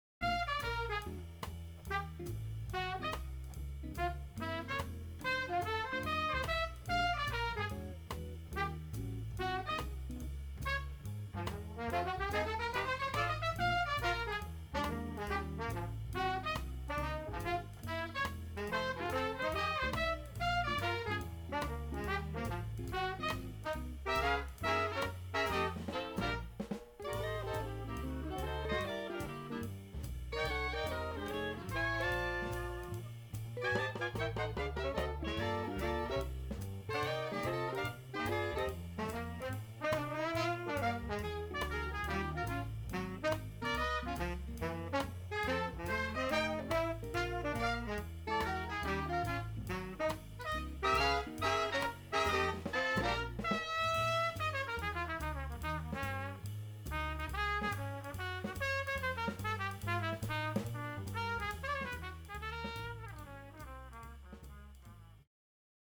Recorded Master Chord Studios January 2017